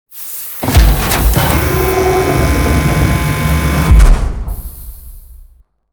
Scrape4.wav